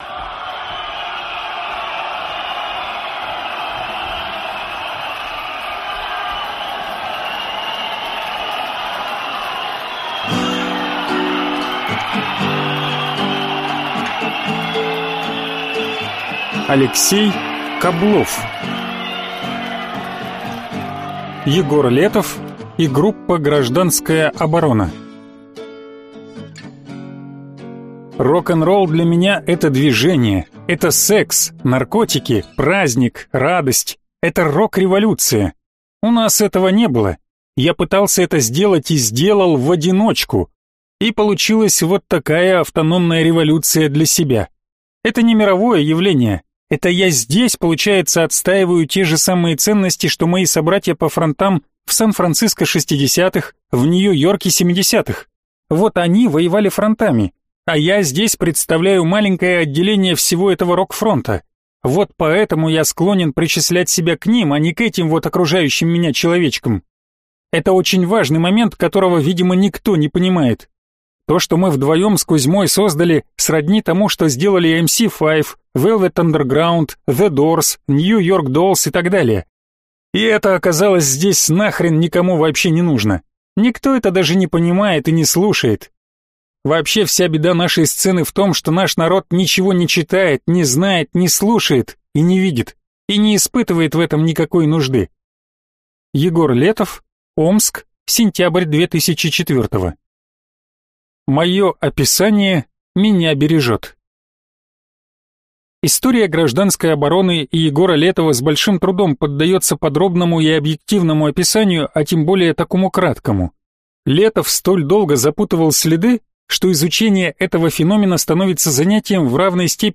Аудиокнига Егор Летов и группа «Гражданская оборона» | Библиотека аудиокниг